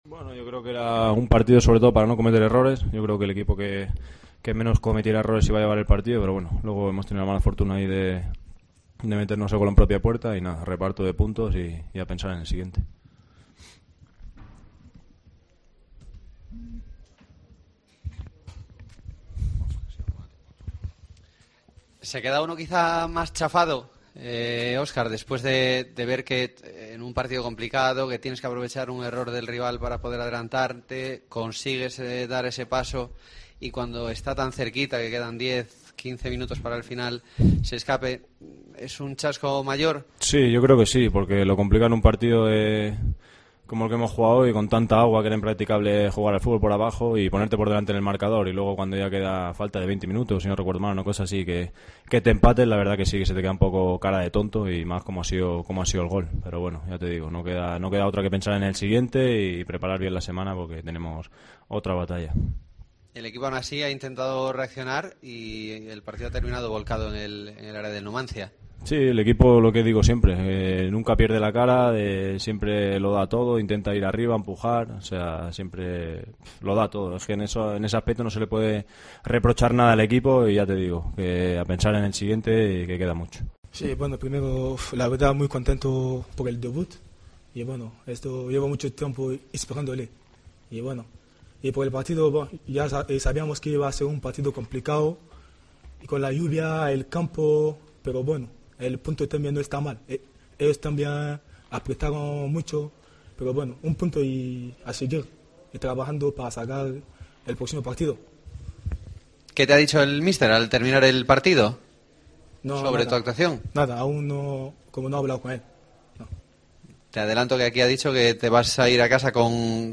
POSTPARTIDO
Escucha aquí las palabras de los dos jugadores de la Deportiva Ponferradina